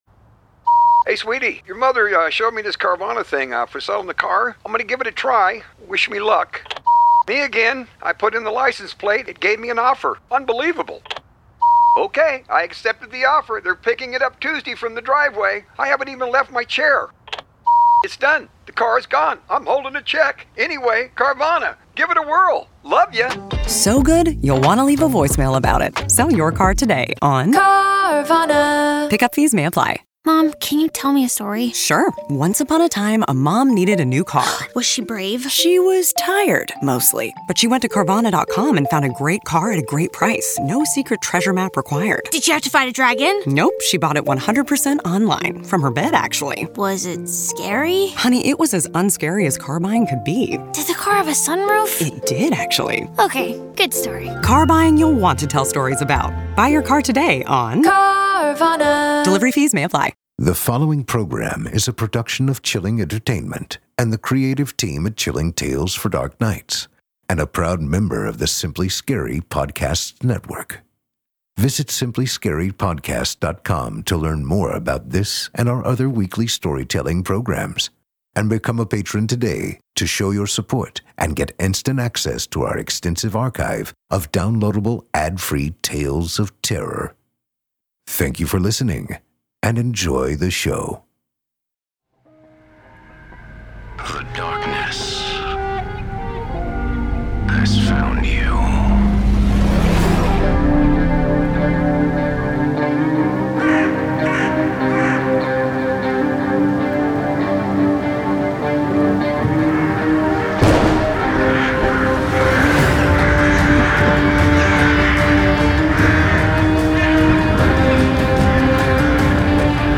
we bring you a tale of terror from author Nick Carlson